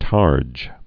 (tärj)